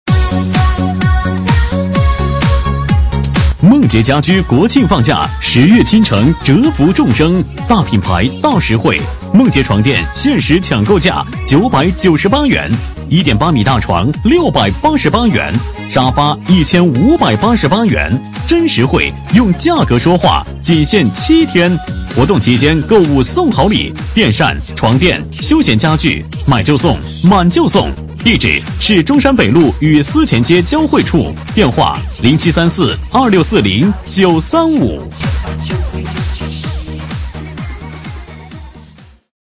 【男19号促销】梦洁家居
【男19号促销】梦洁家居.mp3